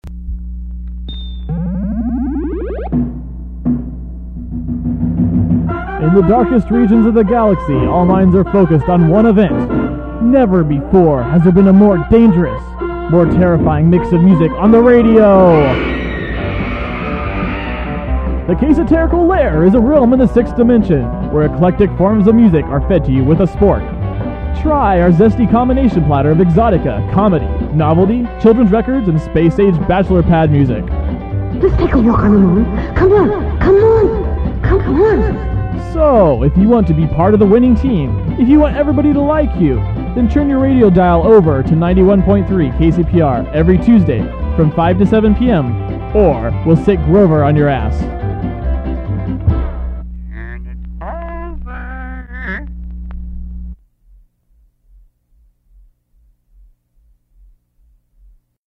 Billy Mure's "Chopsticks Guitar" constitutes the majority of the ad's sound bed, and the advertisement also includes a sample of a scream from Les Baxter and Bas Shiva's "Terror"and a quote from Lucia Pamela's "Walk on the Moon." The ad concludes with a clip from a Sesame Street record. The program itself featured music genres such as Exotica, Space Age Bachelor Pad, Novelty, Comedy, Children's Records, and other esoteric forms.